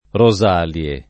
vai all'elenco alfabetico delle voci ingrandisci il carattere 100% rimpicciolisci il carattere stampa invia tramite posta elettronica codividi su Facebook Rosalie [ ro @# l L e ] o Rosarie n. pr. f. pl. stor. — antiche feste romane